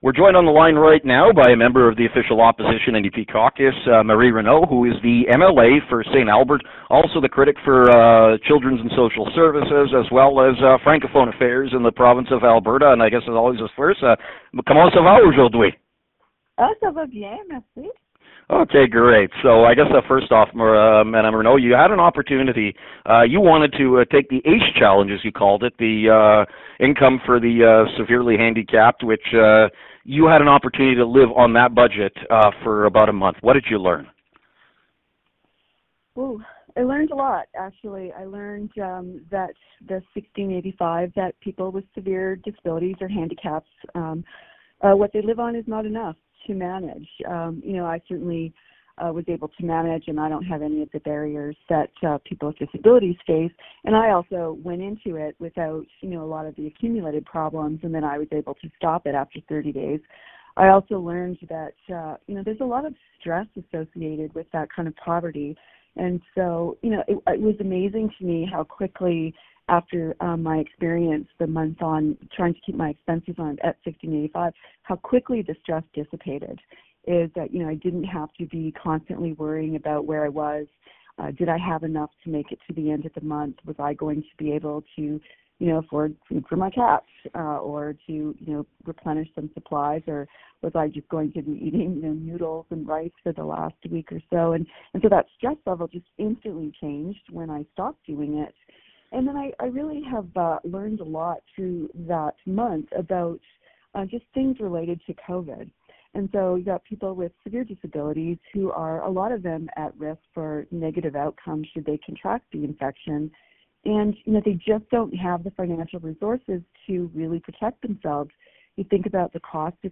NDP Opposition Critic for Community and Social Services, Marie Renaud is this week’s opposition guest on the Trending 55 Hot Seat.